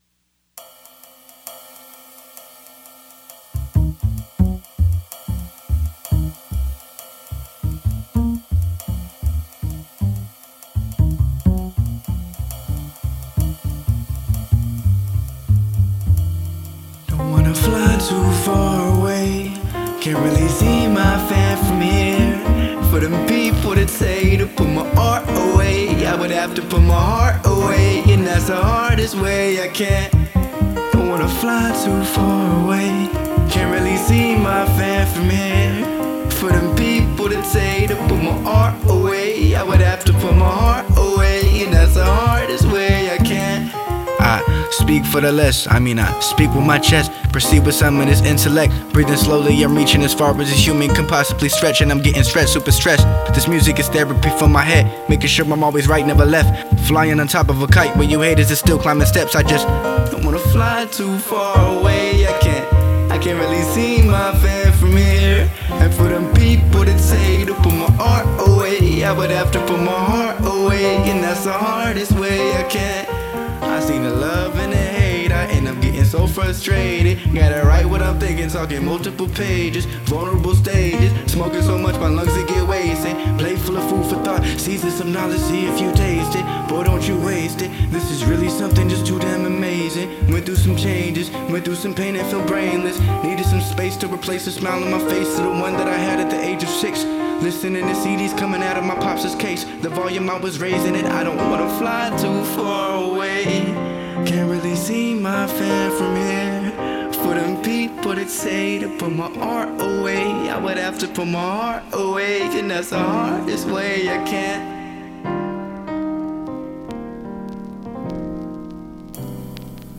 With a tongue-twisting cadence